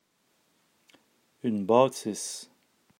Si l’on traverse l’Atlantique, la situation sur le terrain est totalement différente: au Québec et dans les autres provinces canadiennes hébergeant des francophones, la distinction entre les deux A est universellement respectée (dans les syllabes fermées, c’est-à-dire se terminant par un son consonantique), autant en syllabe tonique qu’en syllabe prétonique, comme on peut l’entendre dans les deux paires de mots suivants:
en position prétonique:
Bâtisse.m4a